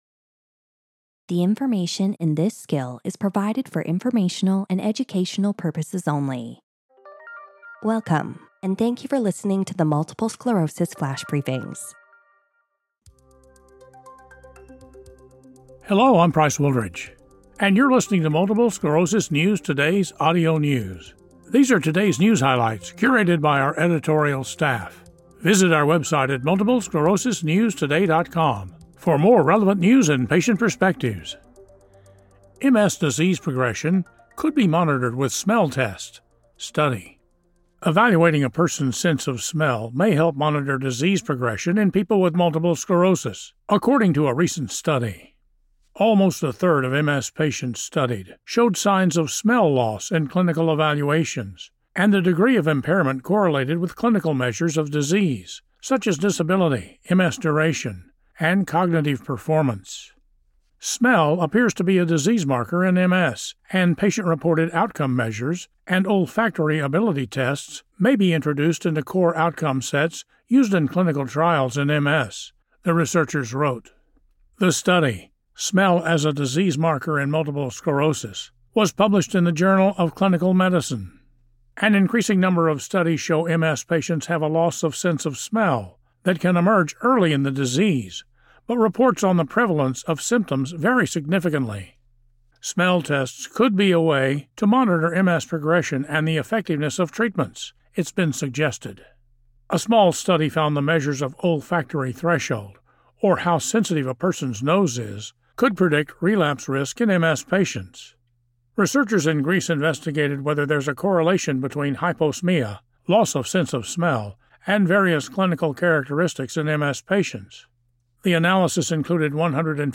reads a news article on how evaluating a person’s sense of smell may help monitor disease progression in people with multiple sclerosis.